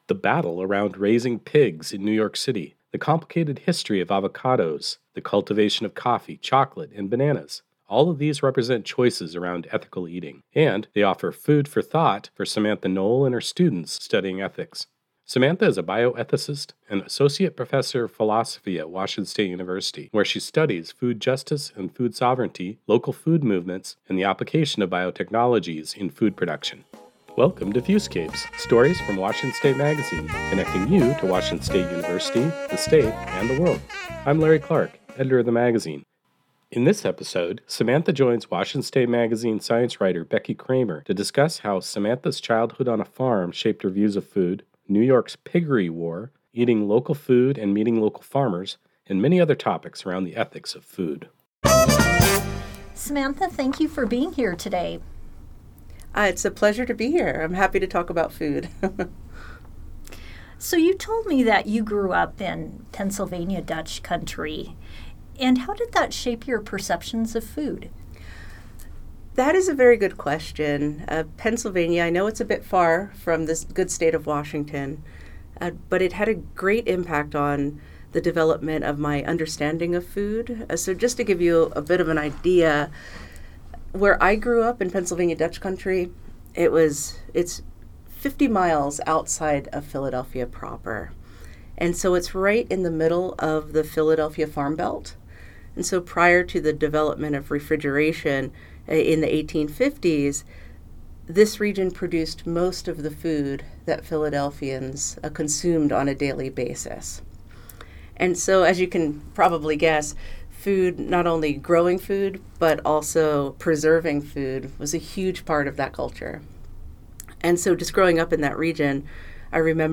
Feeding our ethics: A conversation about food and values